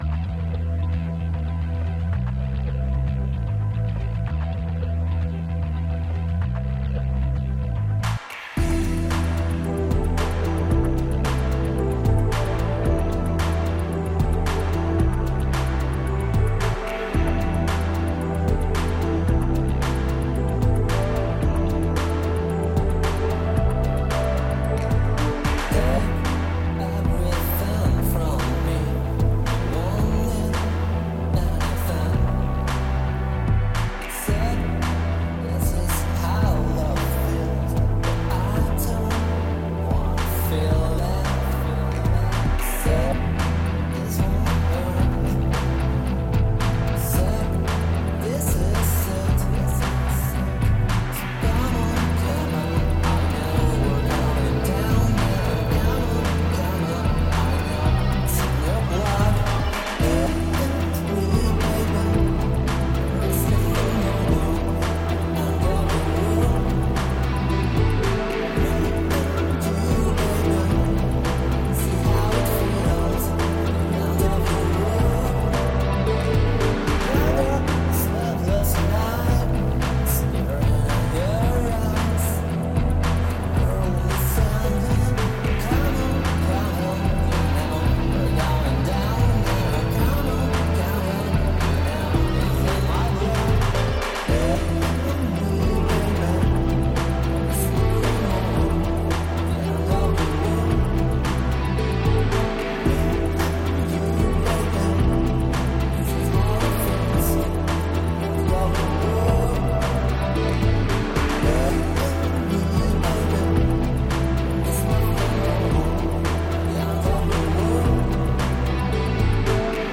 They play lo fi indietronica, indie pop.